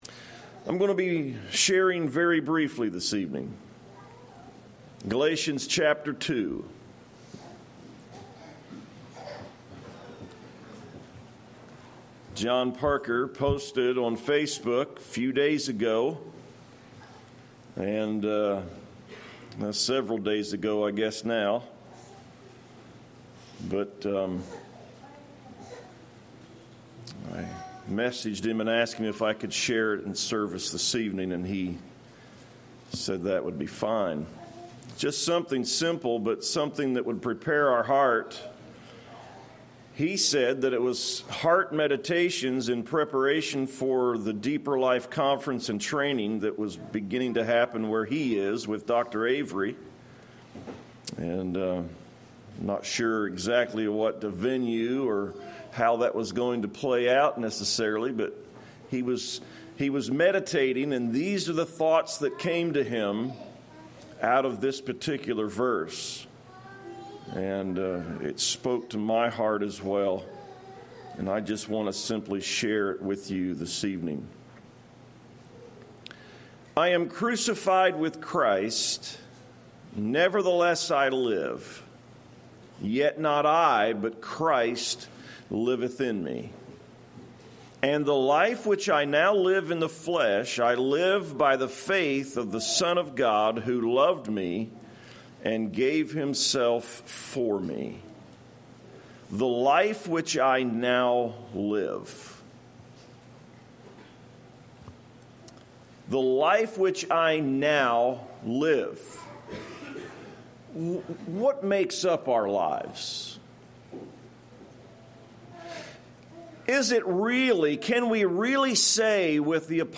A brief message